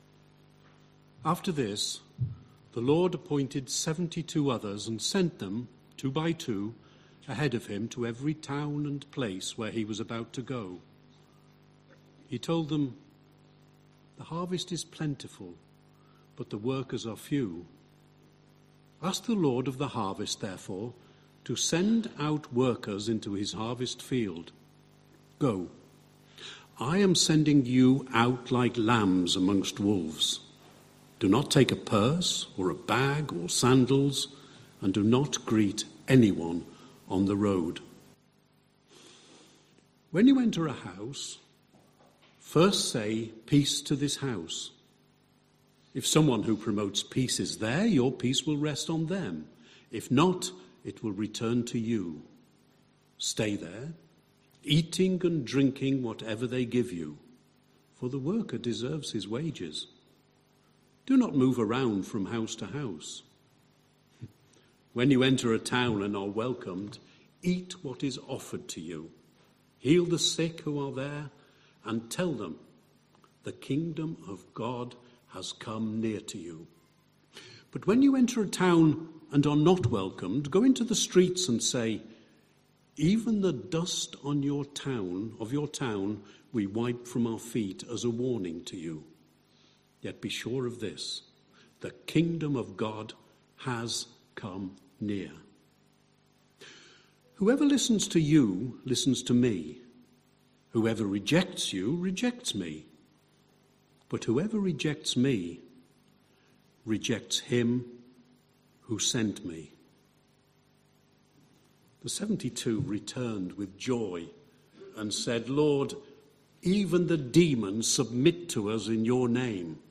13th July 2025 Sunday Reading and Talk - St Luke's